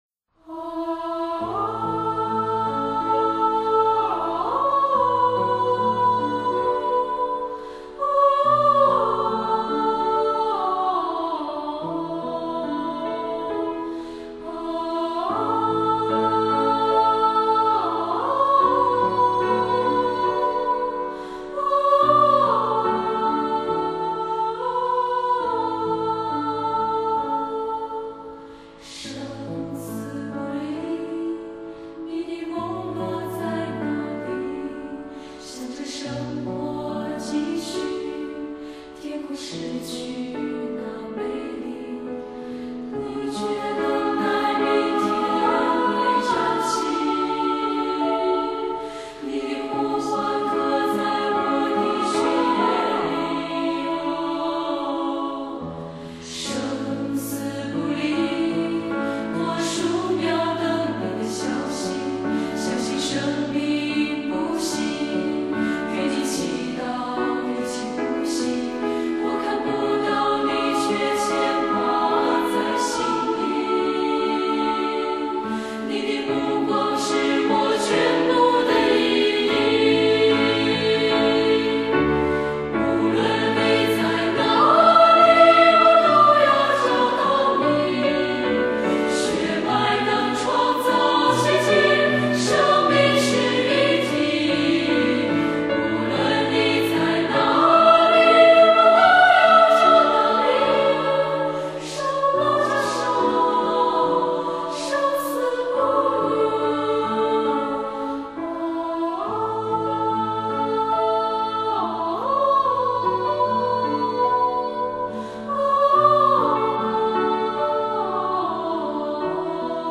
屡获殊荣，扬威国内外的50人女声合唱团
音乐厅现场录制，展现宽广音场
模拟器材后期制作，不放过任何细节